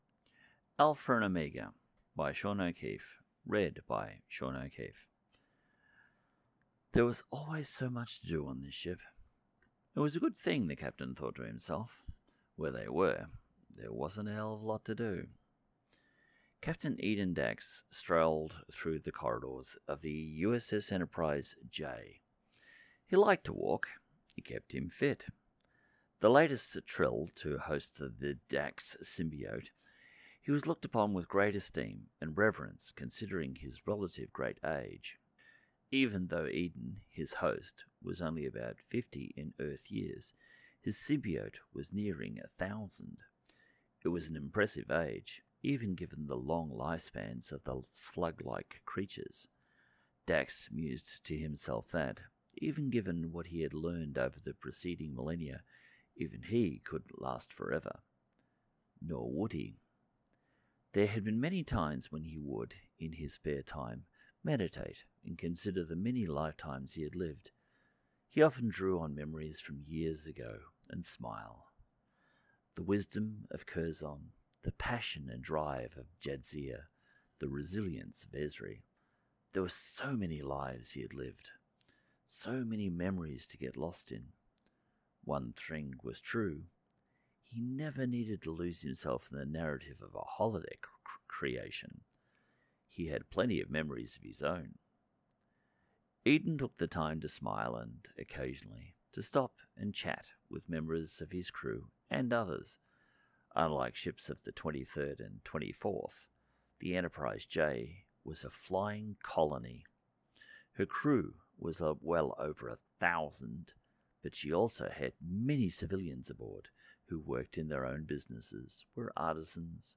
Audio Books/Drama